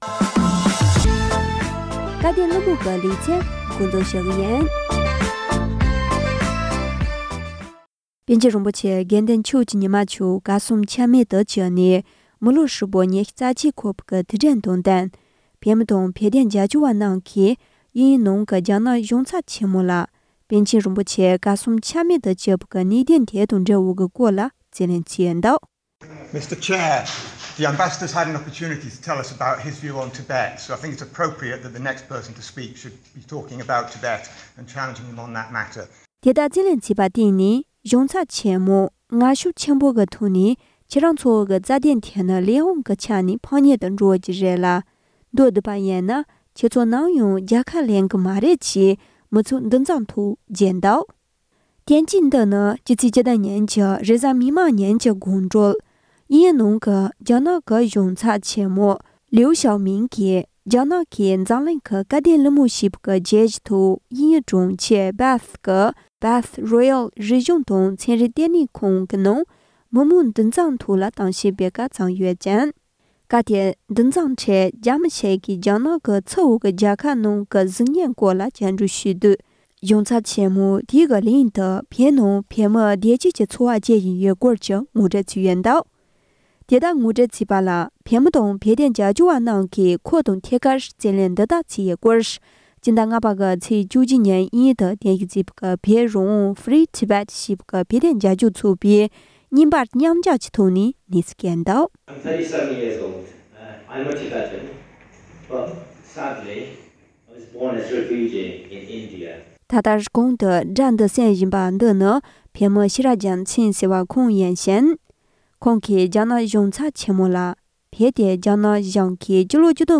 དབྱིན་ཡུལ་དུ་བོད་དོན་རྒྱབ་སྐྱོར་བས་རྒྱ་ནག་གཞུང་ཚབ་ལ་ཁ་གཏད་བཅག་པ། སྒྲ་ལྡན་གསར་འགྱུར།